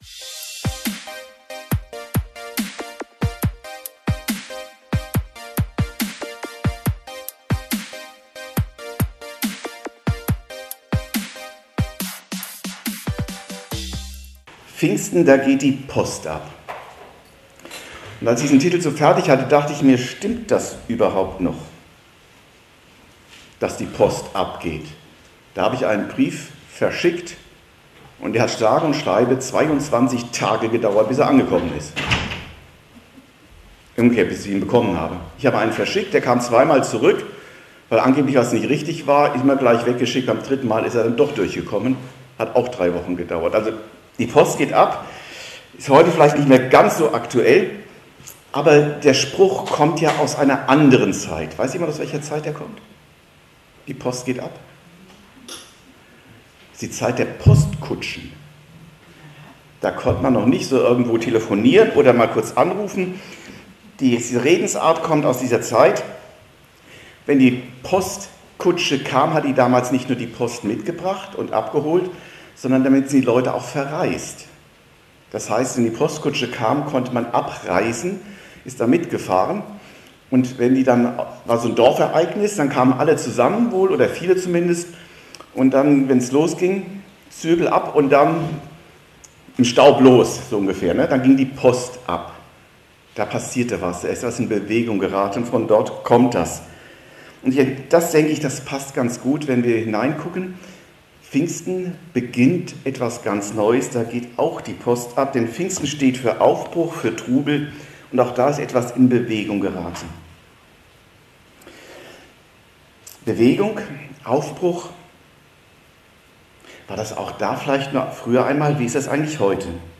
A02 Seit Pfingsten geht die Post ab ~ Predigten u. Andachten (Live und Studioaufnahmen ERF) Podcast